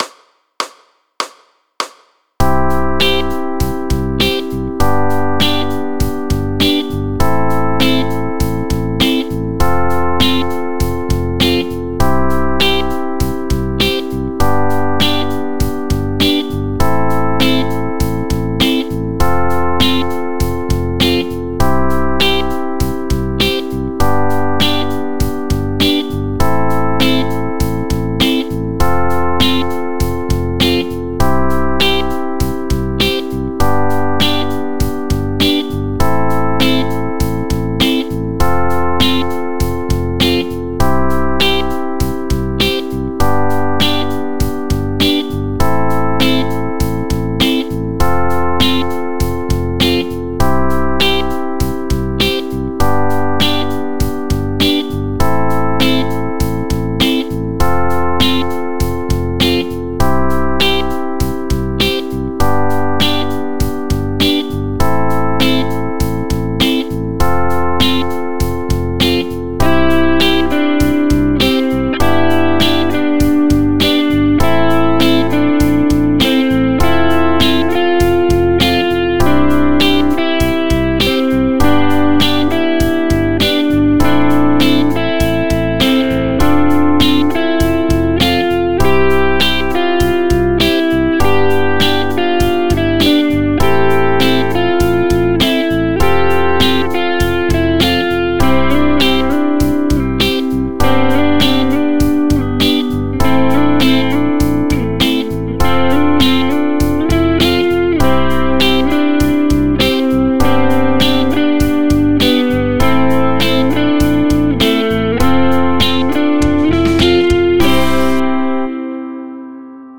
Here’s a backing track that you can use to try out your own tension and release.
I-V-vi-IV Backing Track